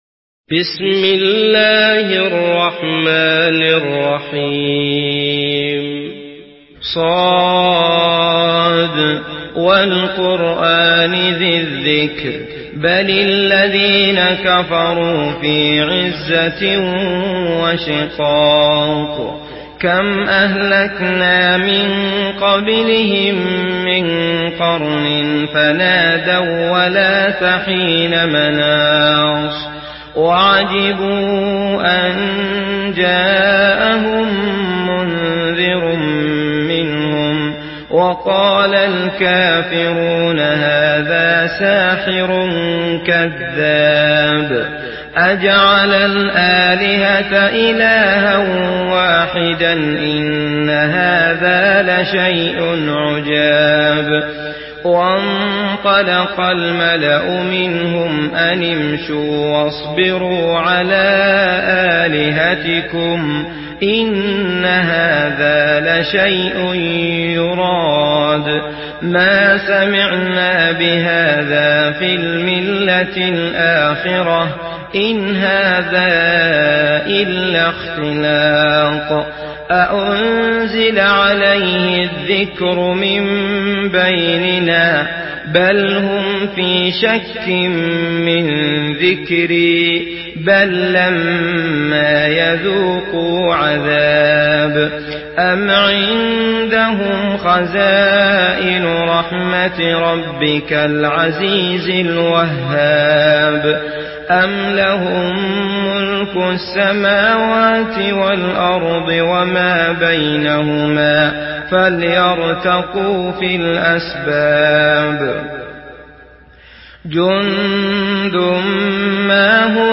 Surah Sad MP3 by Abdullah Al Matrood in Hafs An Asim narration.
Murattal Hafs An Asim